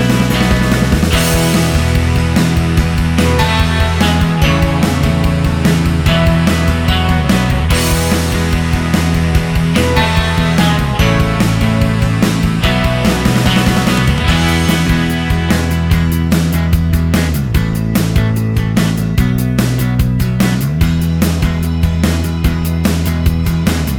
No Sax Solo Rock 4:28 Buy £1.50